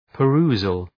perusal.mp3